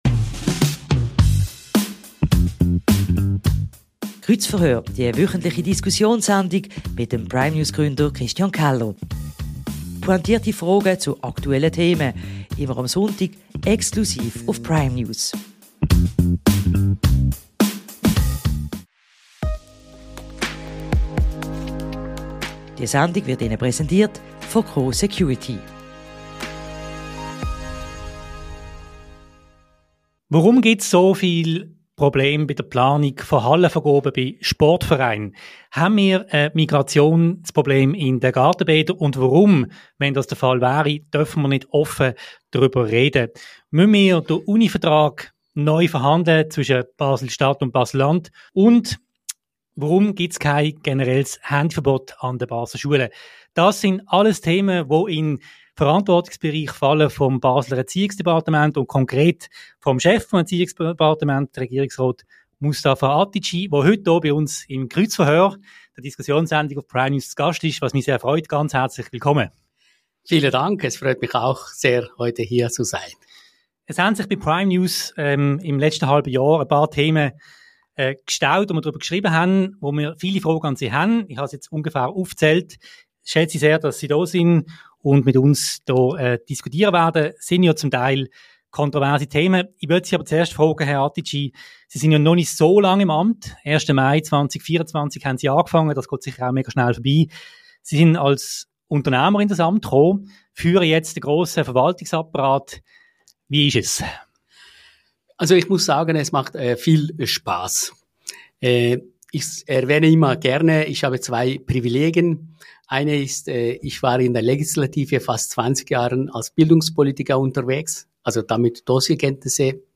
Unmut bei Sportvereinen, Kritik von Bademeistern: Über dies und mehr sprechen wir mit dem SP-Regierungsrat im Kreuzverhör.